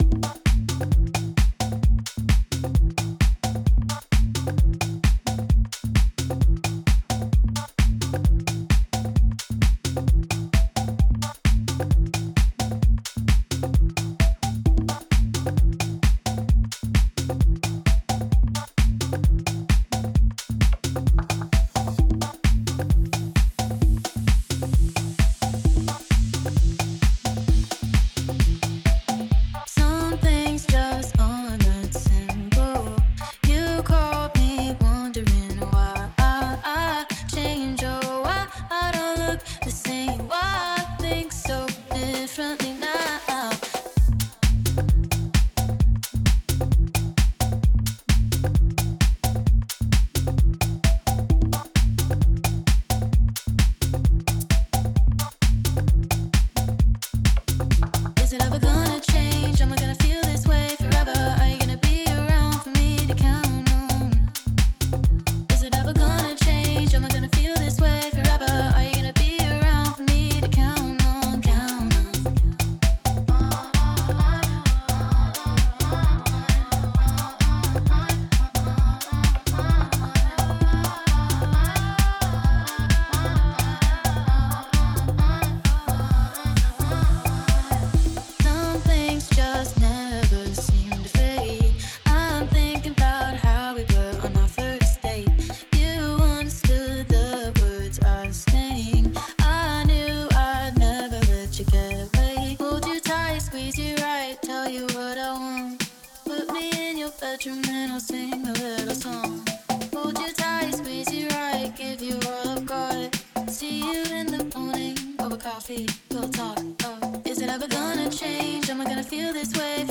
minimal-tech and house